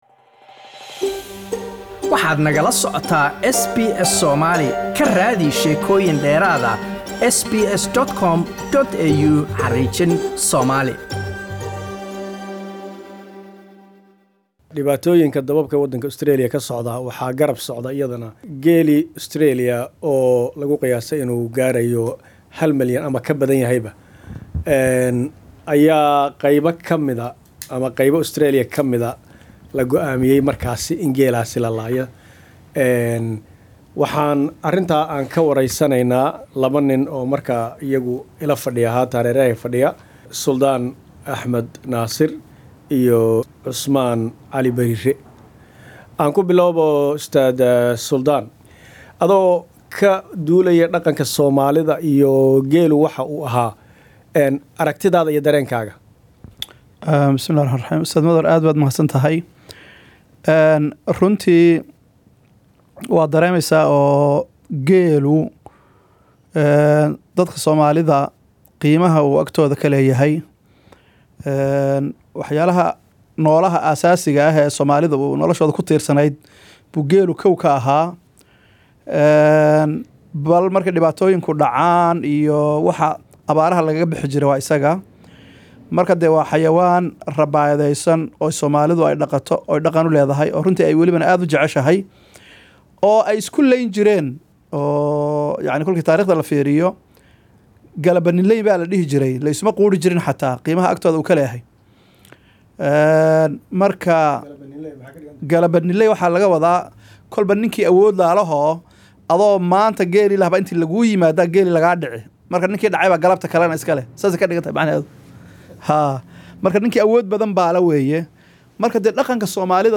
Qayb ka mida geela Australia ayaa la bilaabay in la laayo, iyadoo loo arkay in uu khatar ku hayo nolasha dadka iyo duunyada kaleba, si gaara xiliyada dhulal badani ay gubanayaan wadanka Australia. Talaabadan ayaa dareen ku dhalisay dad ay ka mid yihiin Soomalida oo dhaqata geela. waraysigan ayaan ku eegaynaa dareenkaas.